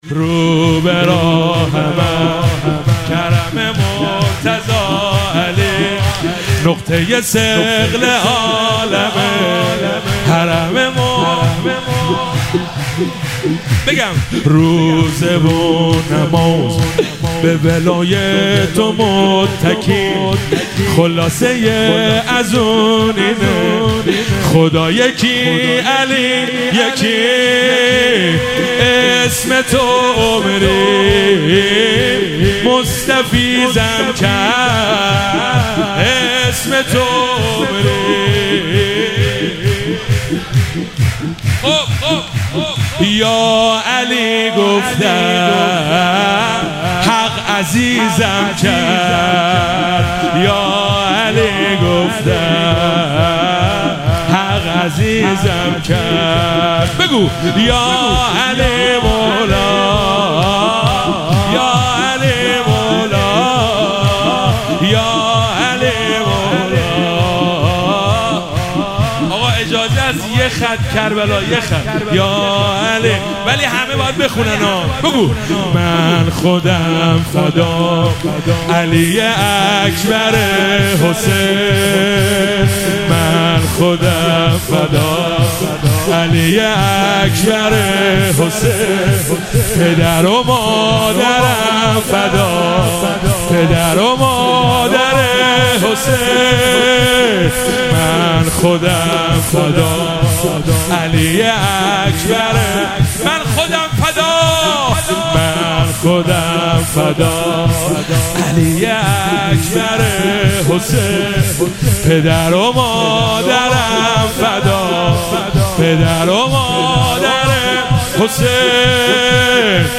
مراسم جشن شب سوم ویژه برنامه عید سعید غدیر خم 1444
شور- رو به راهم از کَرَم مرتضی علی